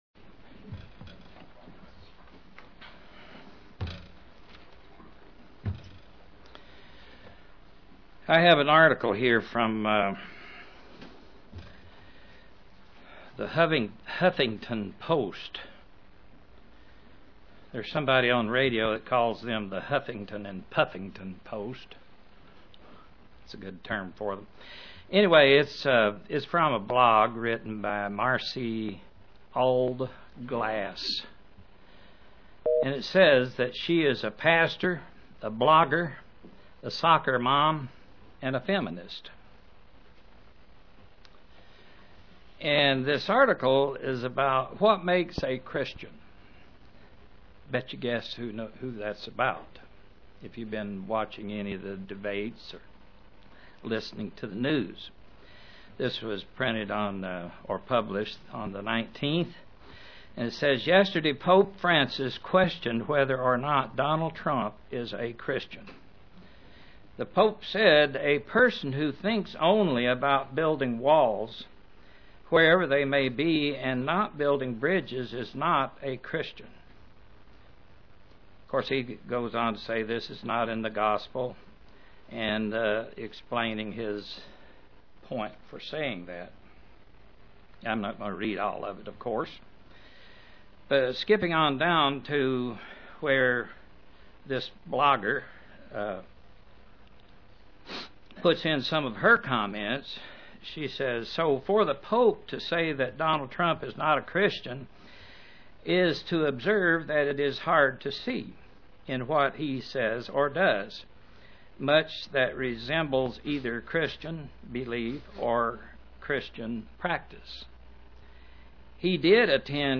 Many in this world claim the title Christian but what does the Bible say that is required to be a true Christian? (Presented to the London KY, Church)